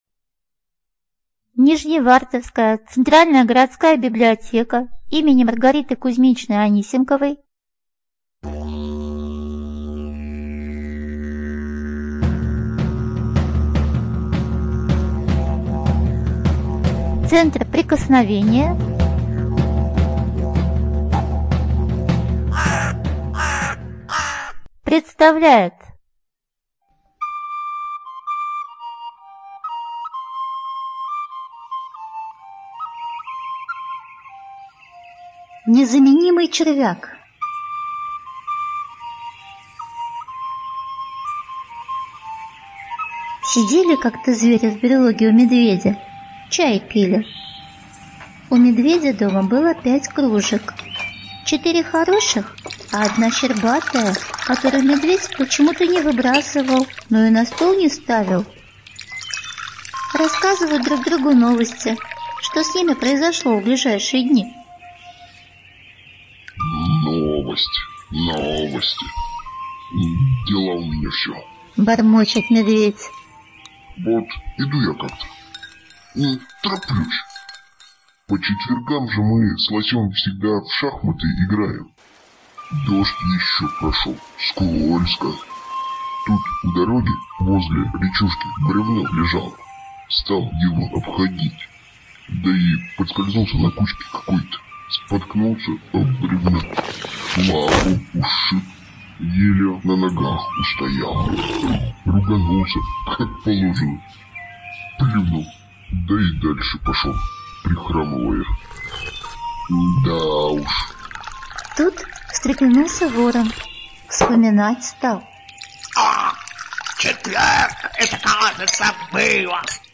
Студия звукозаписиНижневартовская центральная городская библиотека